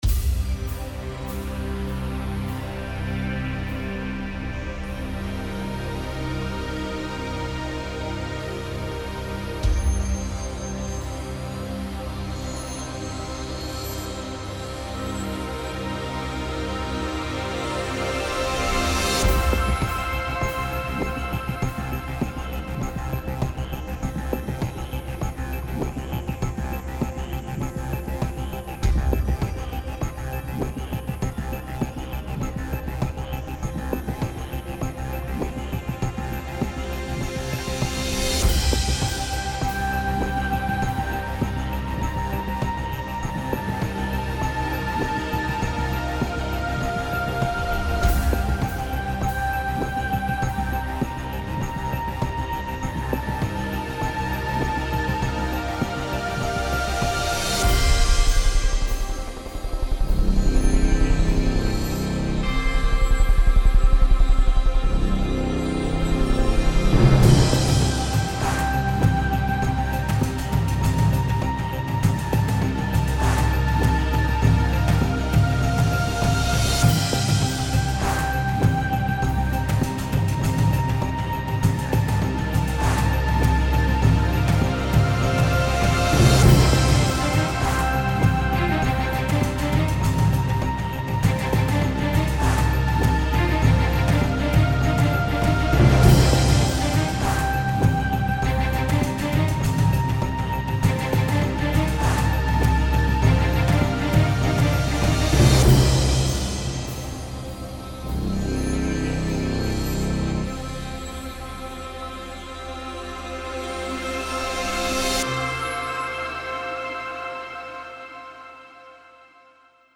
来自另一个世界的下一代科幻声音和音轨。
此免版税的声音包总共包含15条音轨，这些音轨也分为各自的部分（鼓，旋律，fx，打击，合唱等等）。